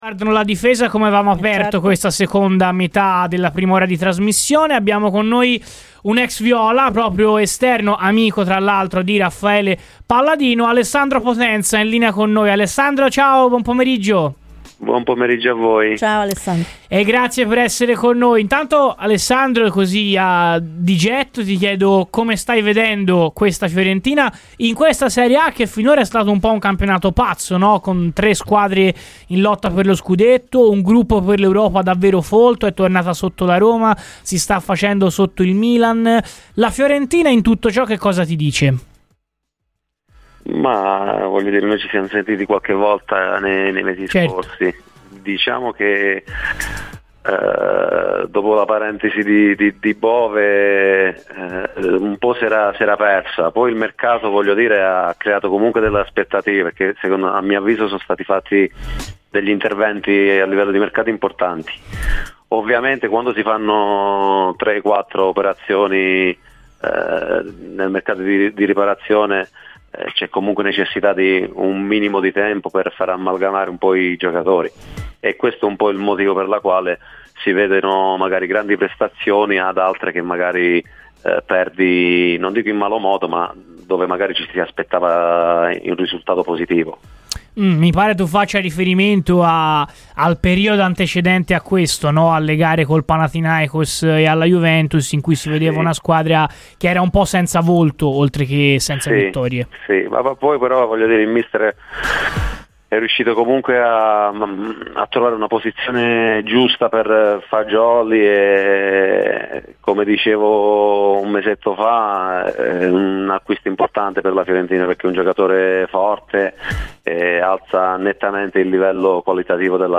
Radio FirenzeViola